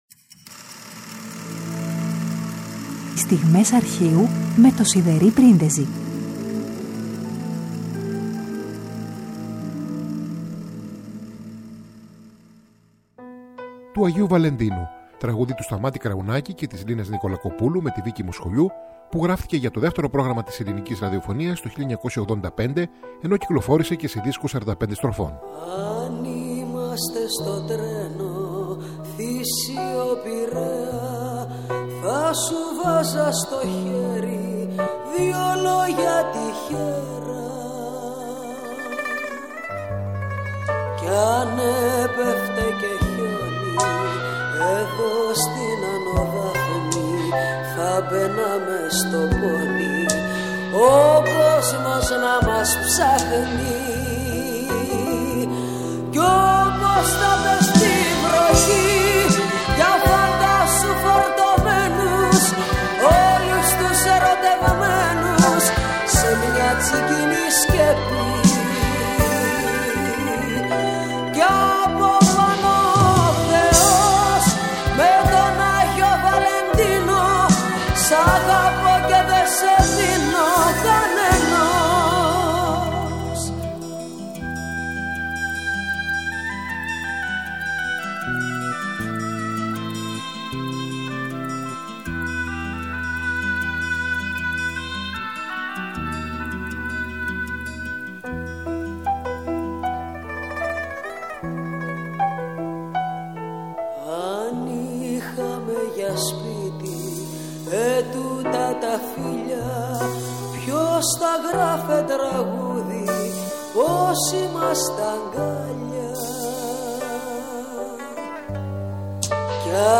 τραγούδια ηχογραφημένα στο ραδιόφωνο